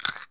comedy_bite_chew_03.wav